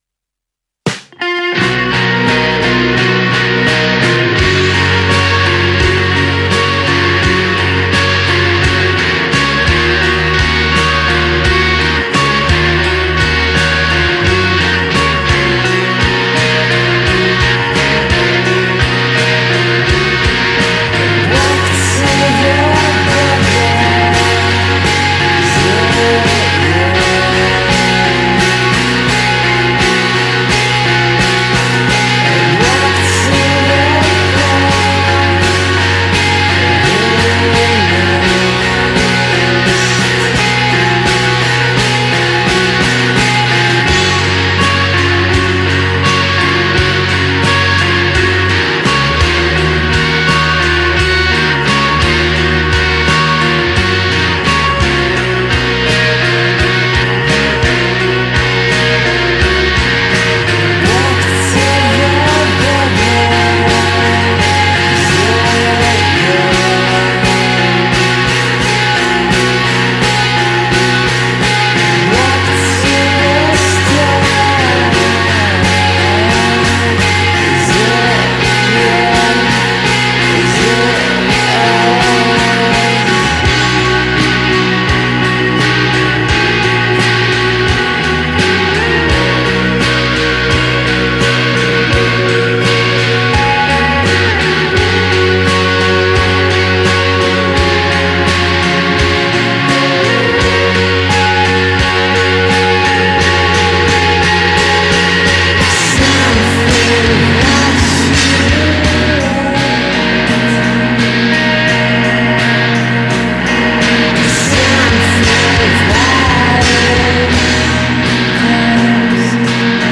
guitar & vocals
bass
drums